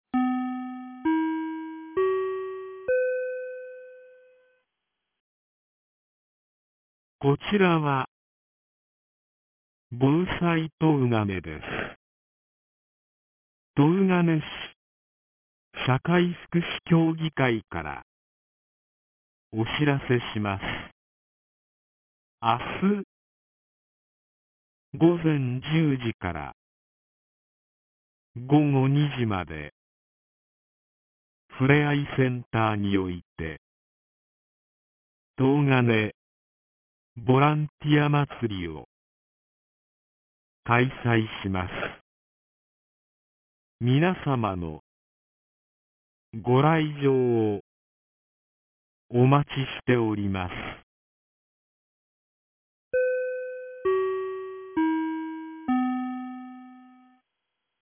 2023年08月10日 16時01分に、東金市より防災行政無線の放送を行いました。